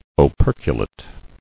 [o·per·cu·late]